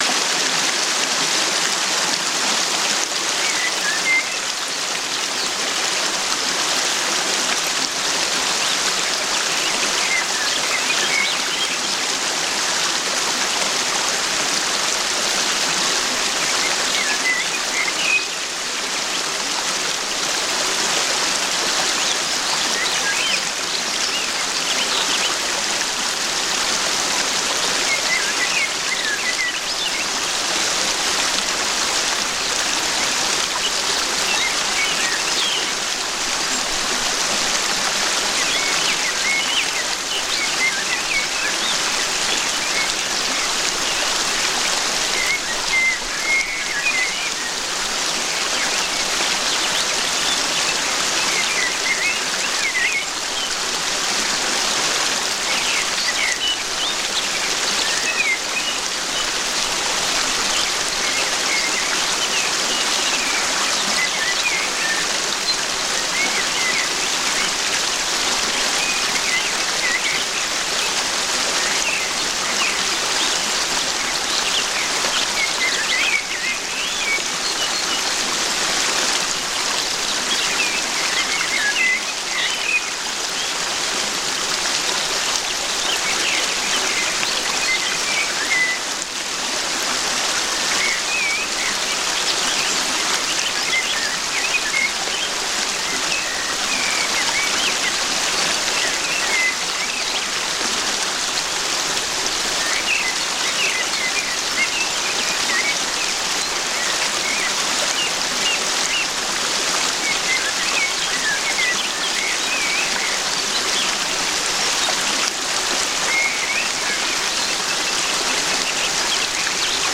SEELEN-ENTSPANNUNG: Küstenbrise-Beruhigung mit Ozeanwind